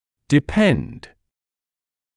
[dɪ’pend][ди’пэнд]зависетьto depend on/upon somebody/something зависеть от кого-то/чего-то